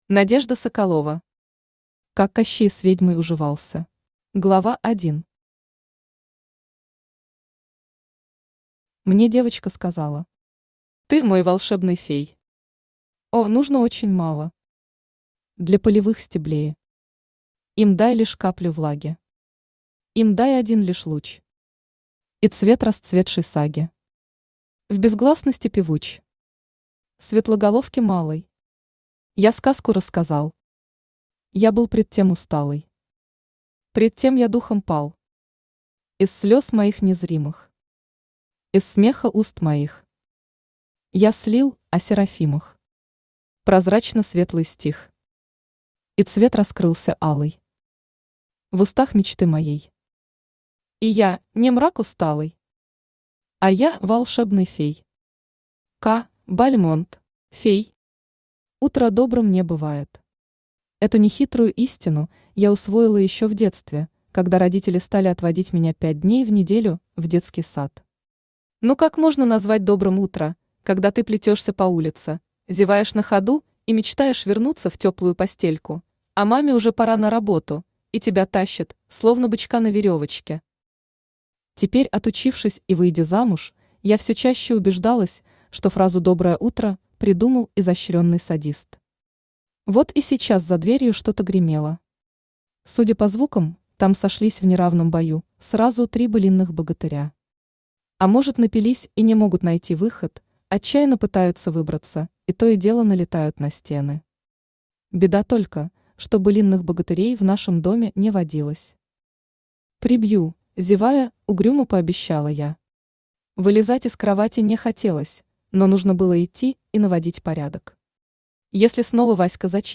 Аудиокнига Как Кощей с ведьмой уживался | Библиотека аудиокниг
Aудиокнига Как Кощей с ведьмой уживался Автор Надежда Игоревна Соколова Читает аудиокнигу Авточтец ЛитРес.